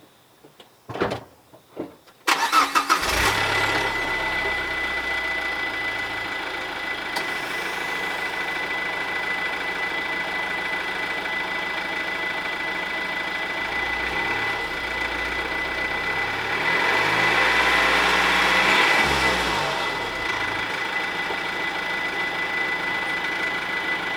いつでも排気音が聴けるように置いておきます。
よい音でした。